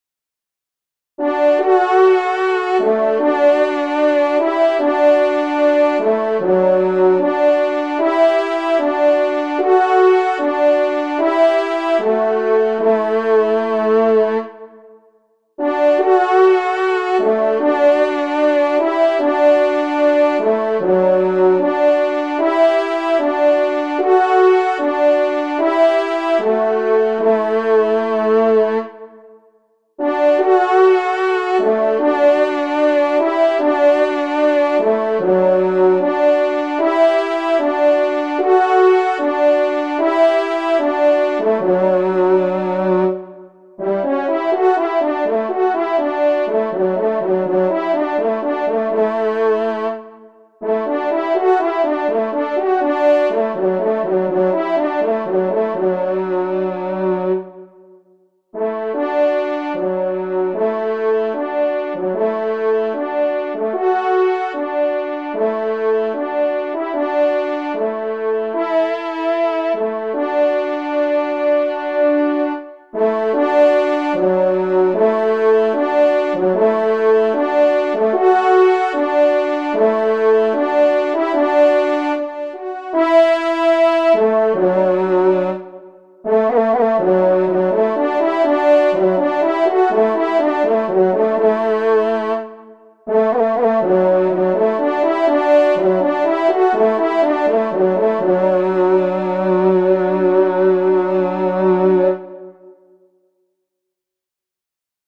Genre : Fantaisie Liturgique pour quatre trompes
Pupitre 2°Trompe